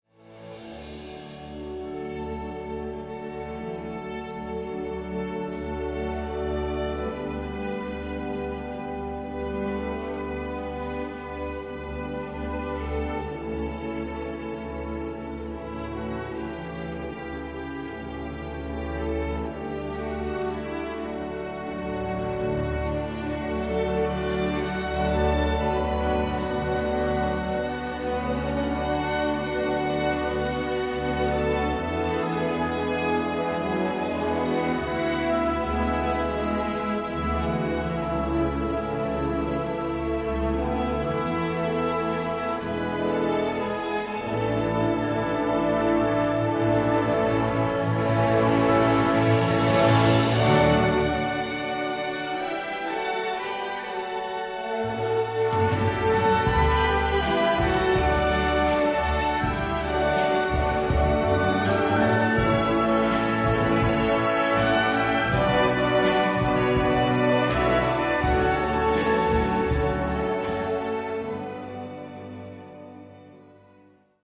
Orchestre seul